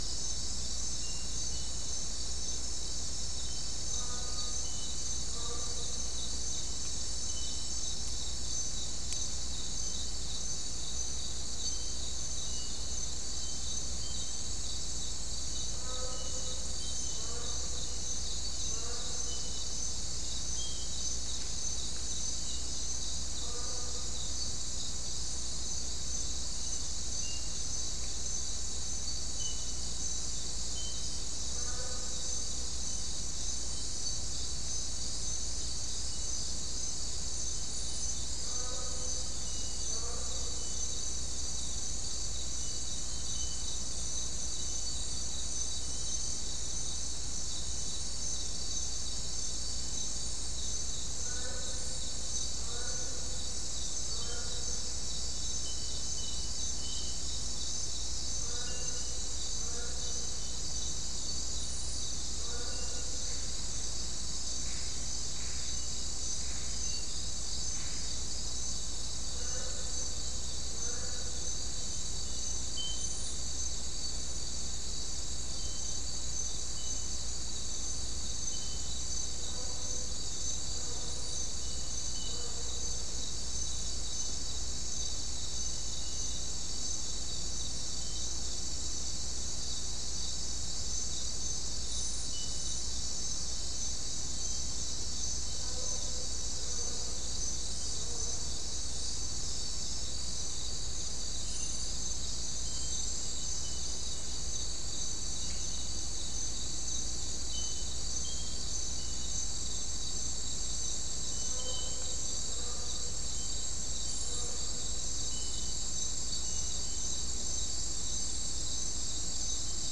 Non-specimen recording: Soundscape Recording Location: South America: Guyana: Mill Site: 2
Recorder: SM3